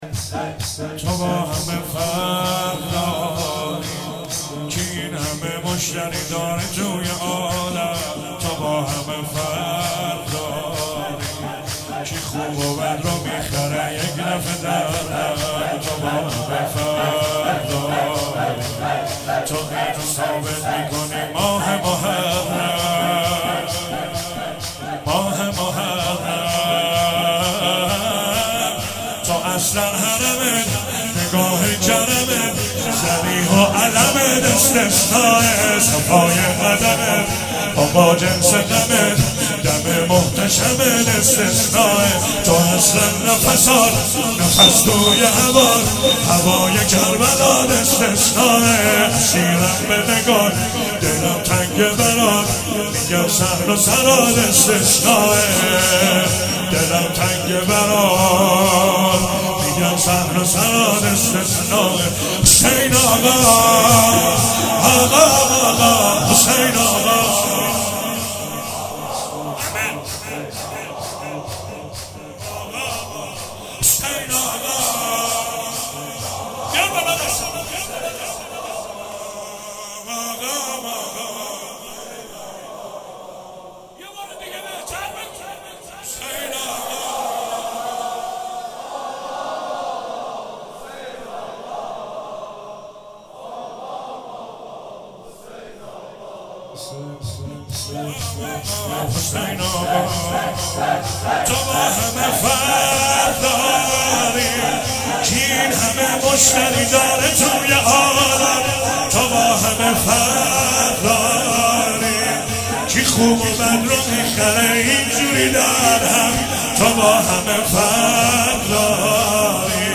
شب سوم محرم 96 - شور - تو با همه فرق داری